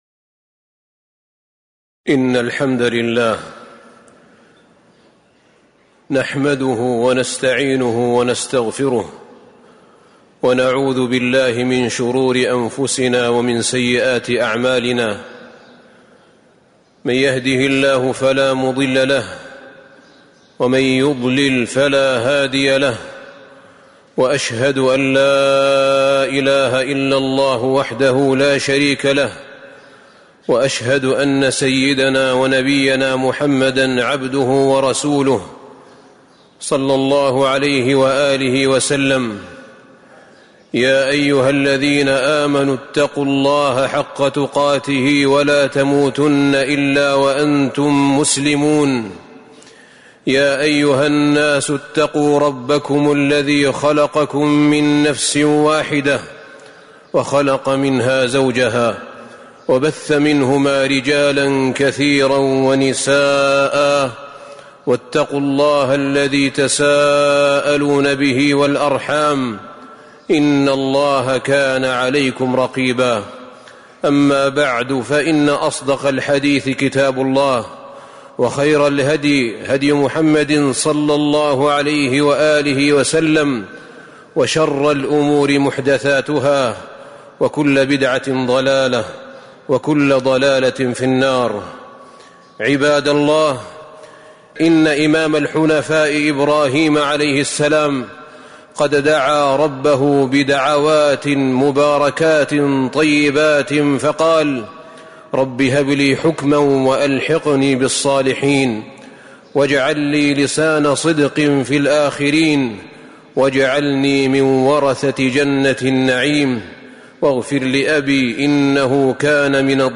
تاريخ النشر ١٨ شعبان ١٤٤٤ هـ المكان: المسجد النبوي الشيخ: فضيلة الشيخ أحمد بن طالب بن حميد فضيلة الشيخ أحمد بن طالب بن حميد من صفات قلوب المؤمنين